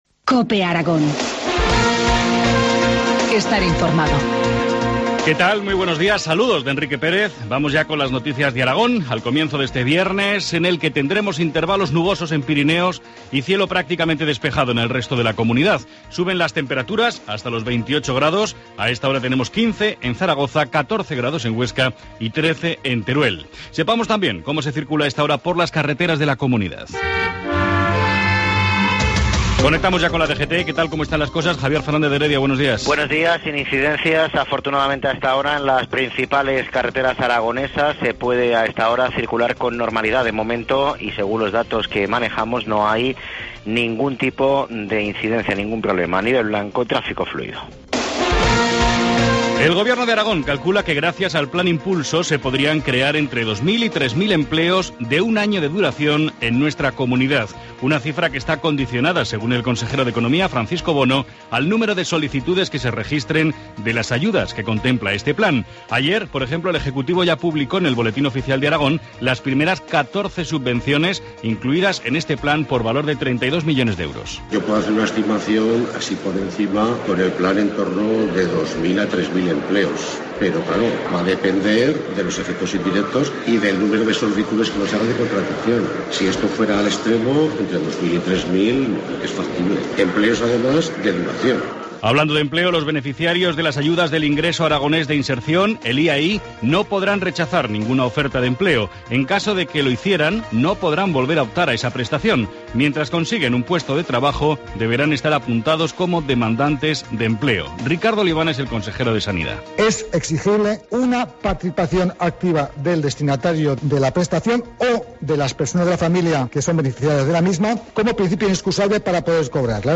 Informativo matinal, viernes 13 de septiembre, 7.25 horas